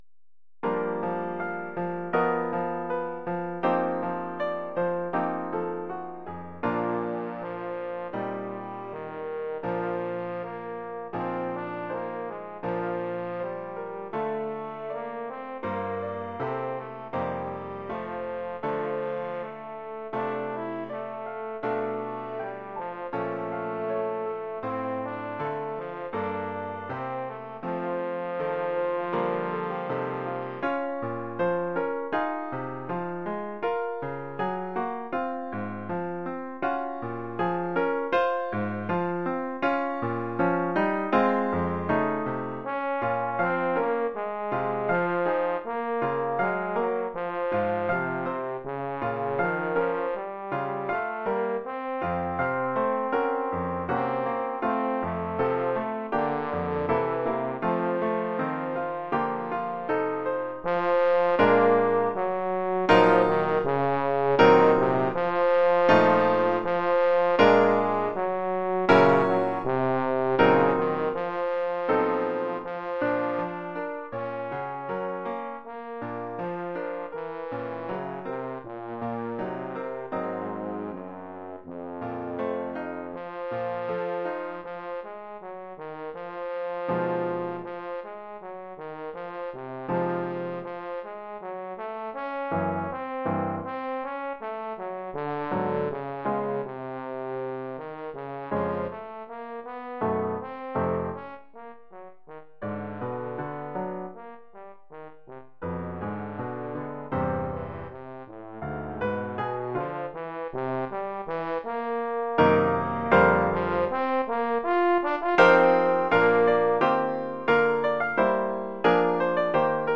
Formule instrumentale : Trombone et piano
Oeuvre pour trombone et piano.